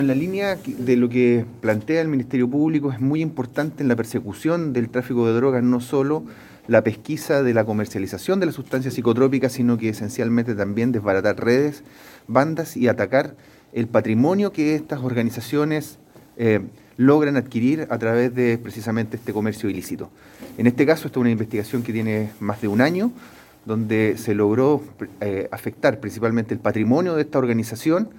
Por su parte el fiscal regional del Ministerio Público, Roberto Garrido, precisó que el trabajo desplegado está además buscando desbaratar económicamente a las bandas dedicadas al trafico de drogas.